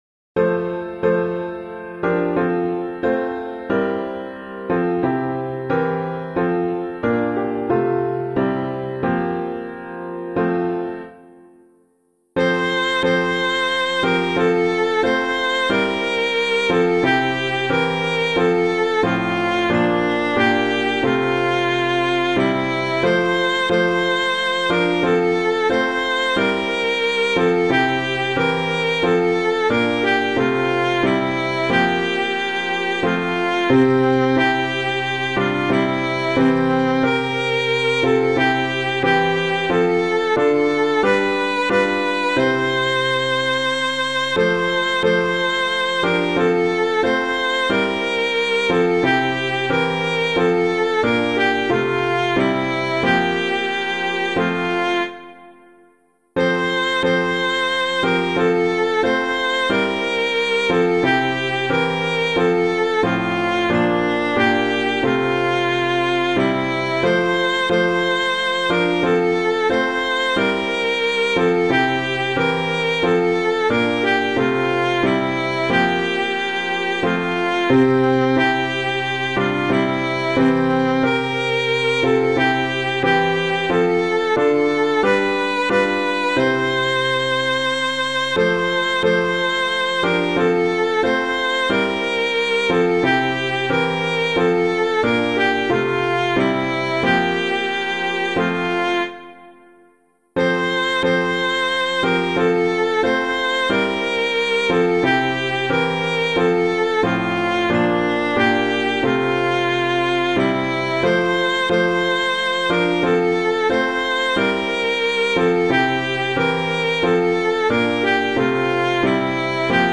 piano
I Sing the Mighty Power of God [Watts - GOSHEN] - piano.mp3